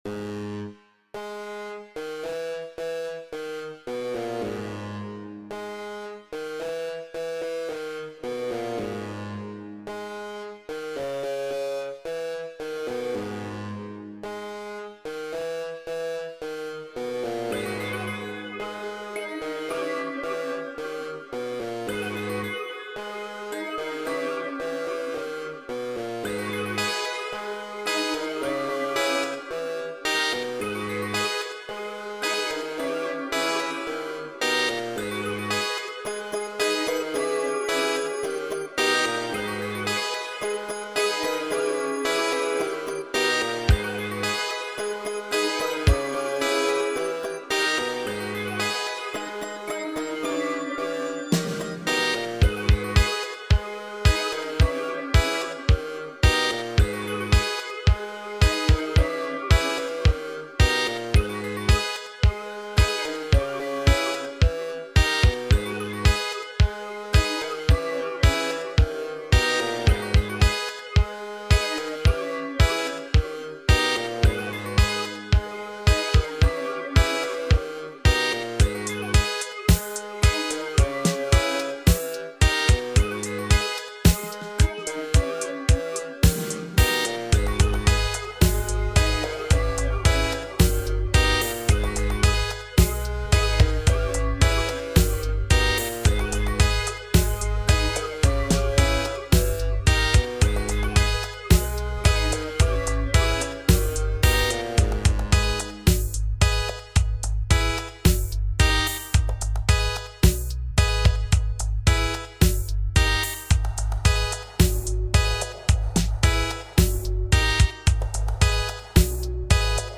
Première production stepper !